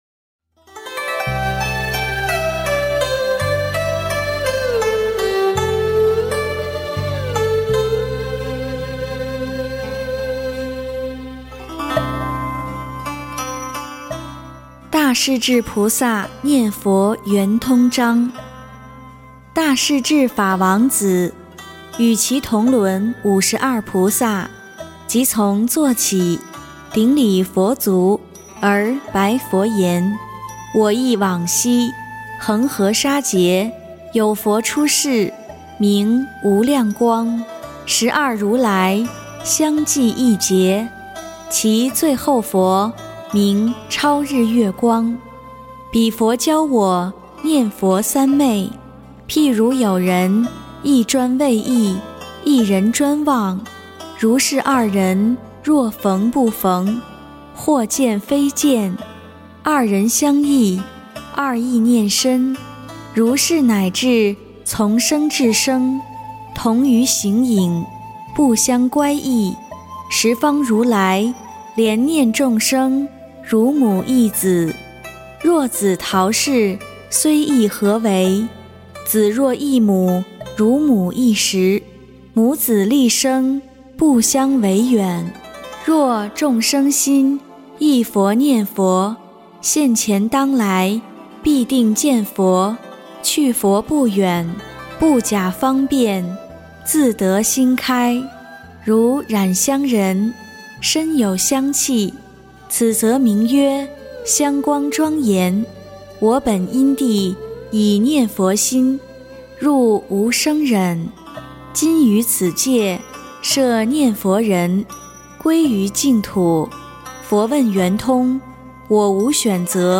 大势至菩萨念佛圆通章（念诵）